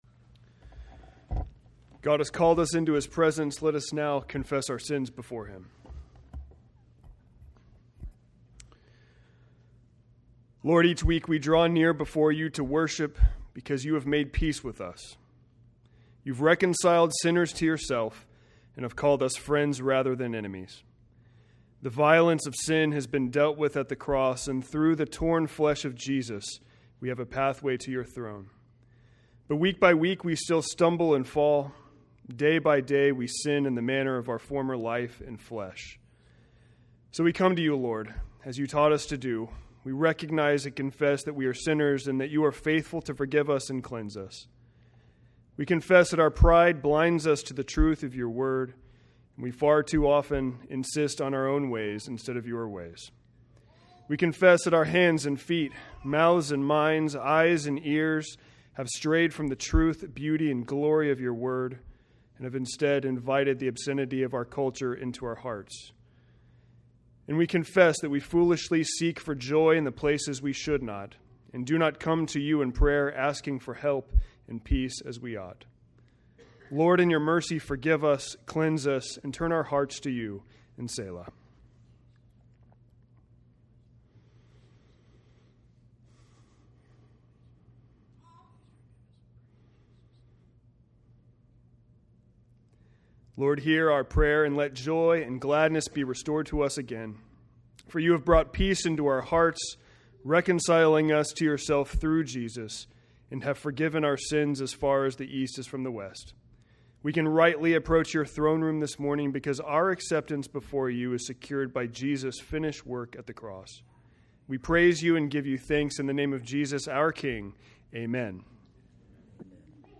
From Series: "Seasonal Sermons"